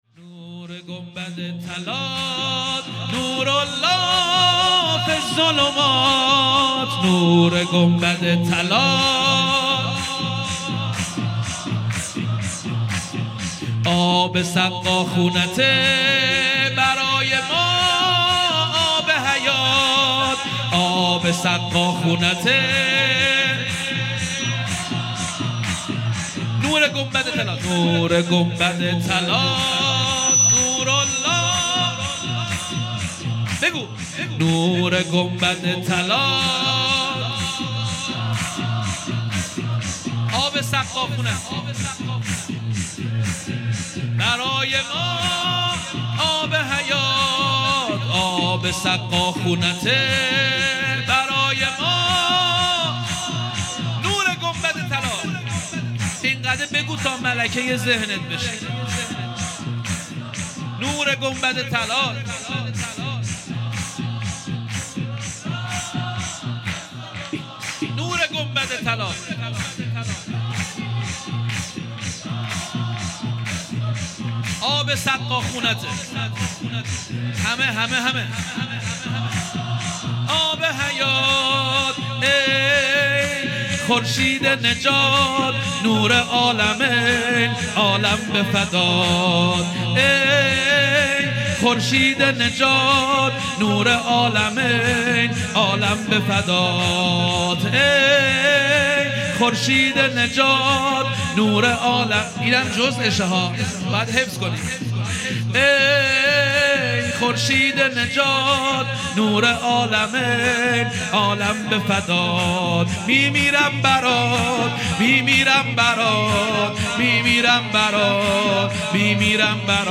0 0 سرود | نور گنبد طلات نورالله فی الظلمات